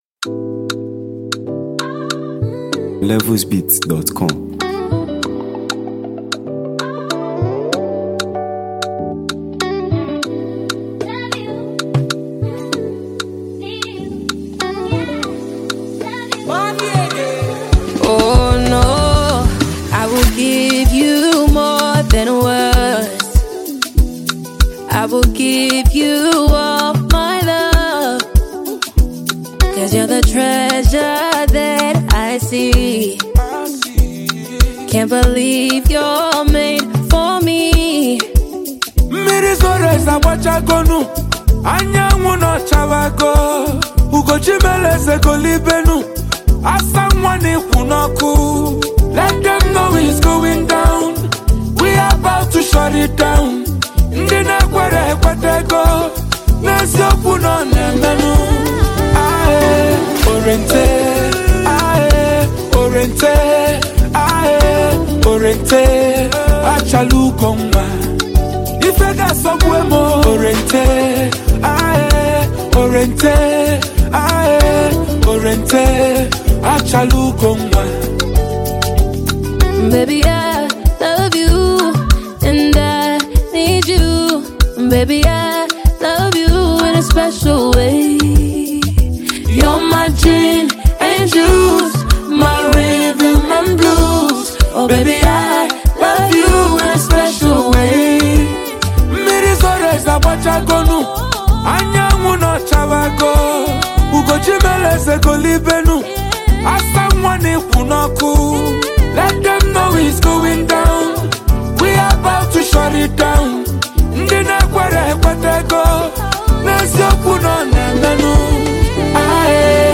a captivating blend of melody, rhythm, and emotion
a riveting and melodious record